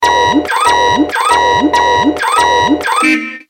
نغمة ايقاع قرع الطبول